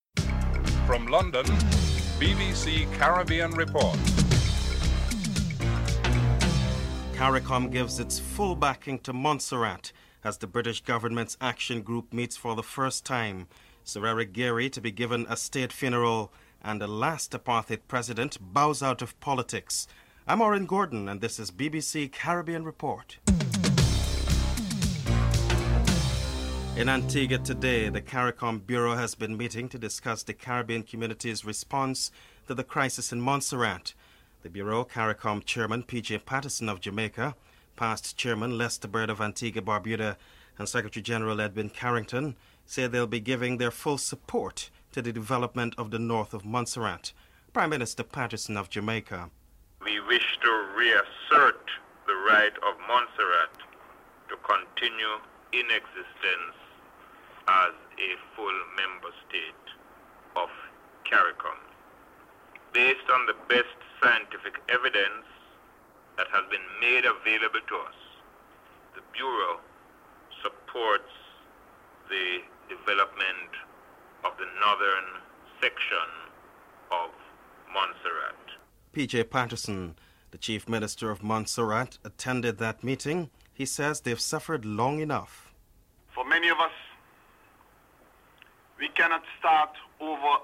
1. Headlines (00:00-00:25)
The last apartheid president bows out of politics. Frederik Willem de Klerk and President Nelson Mandela are interviewed.